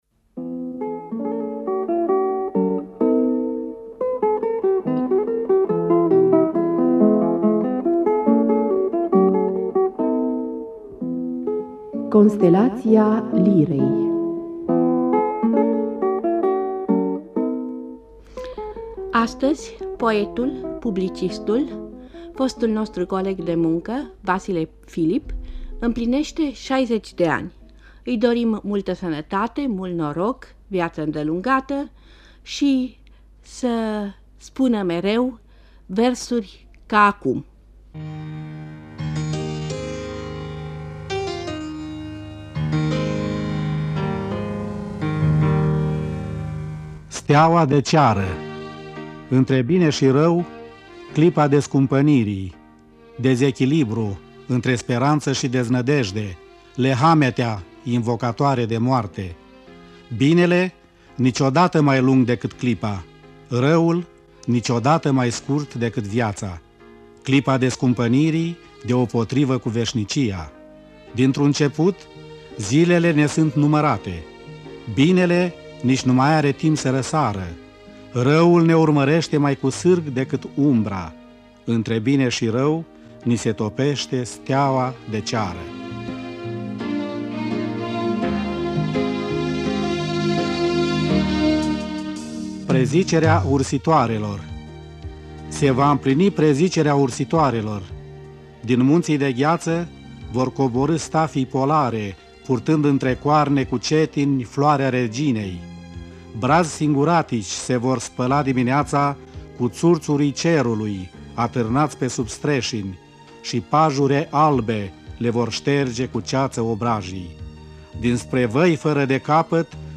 rostea versuri proprii